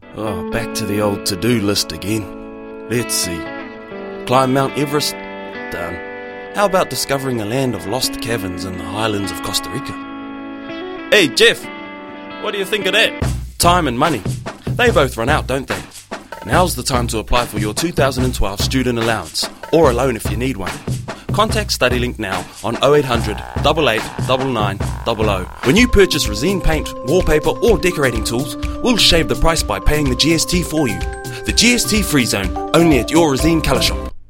Demo
Adult